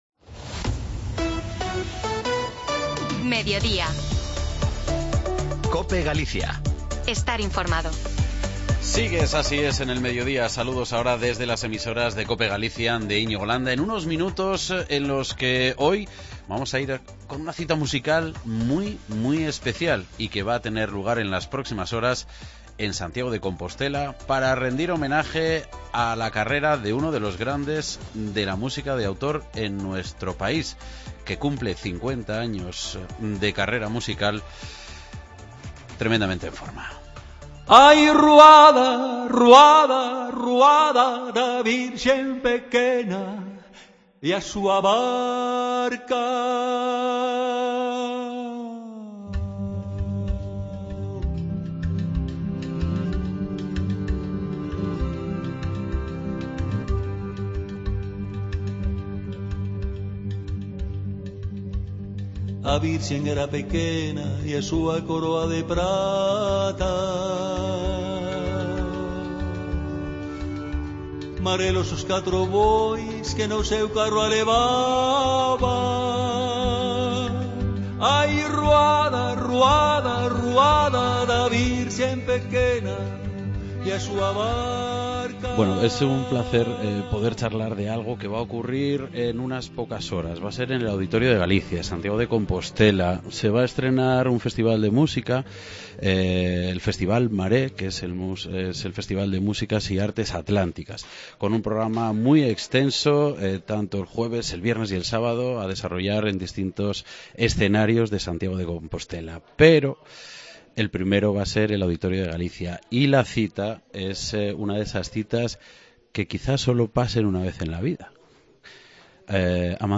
hablamos con el músico leonés Amancio Prada